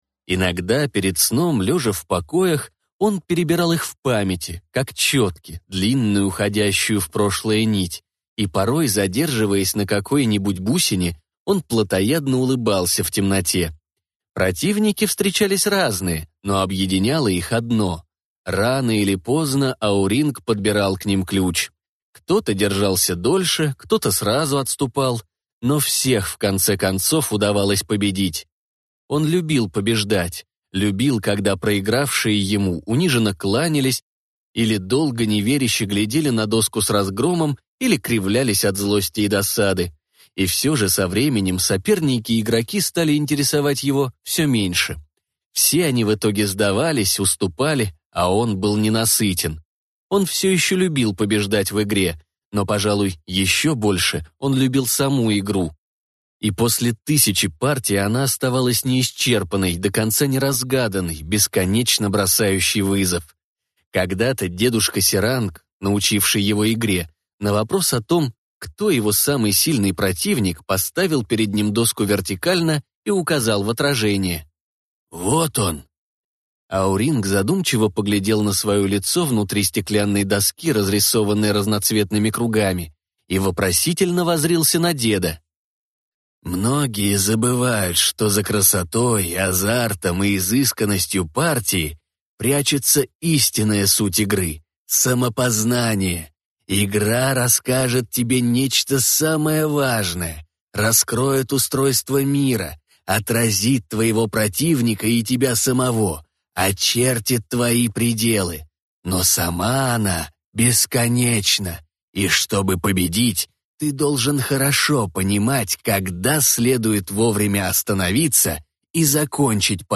Аудиокнига Журнал «Рассказы». Иная свобода | Библиотека аудиокниг